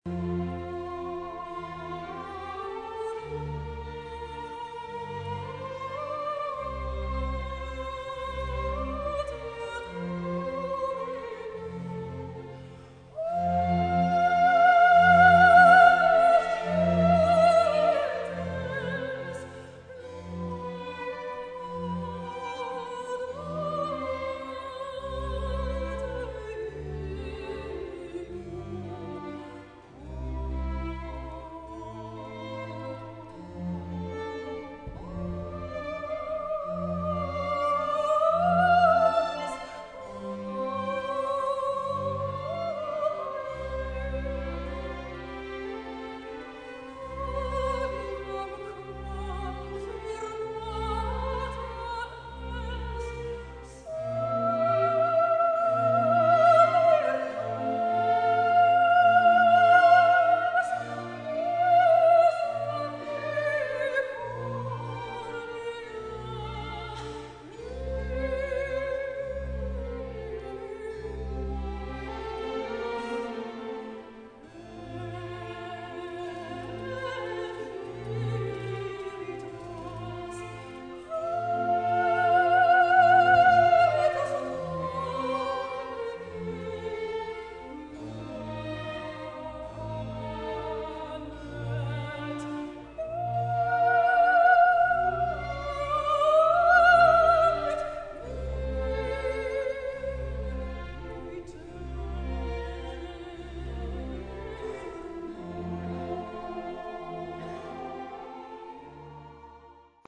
sopraan Muziekfragmenten